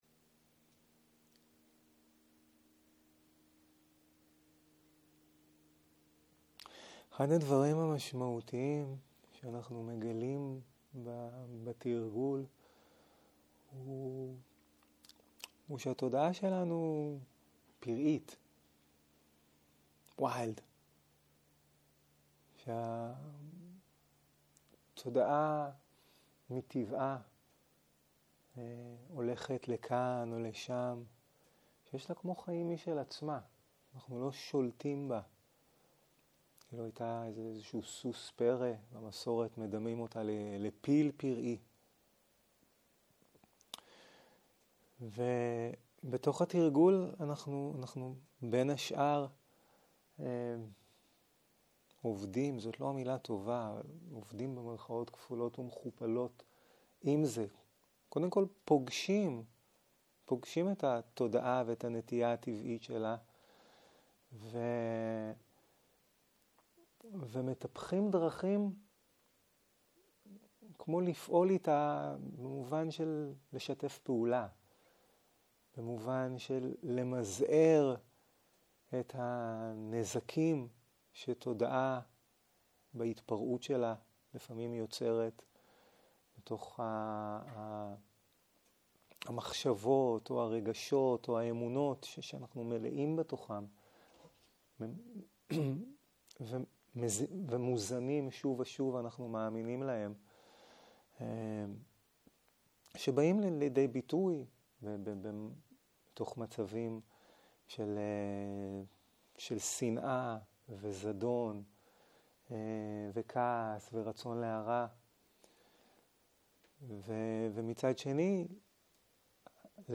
מדיטציה מונחית
Guided meditation